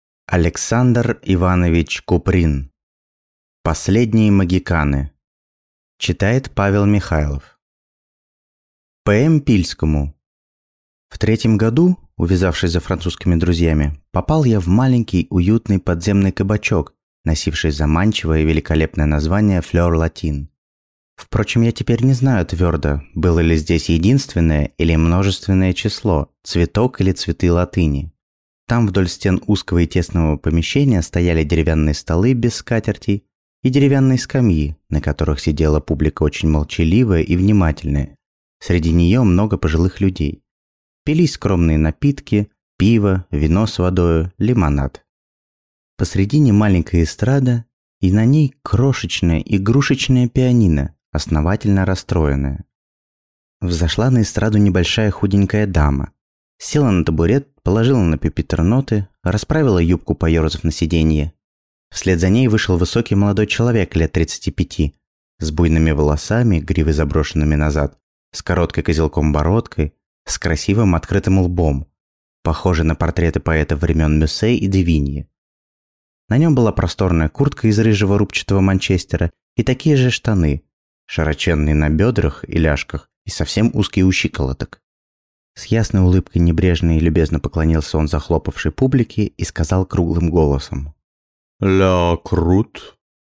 Aудиокнига Последние могиканы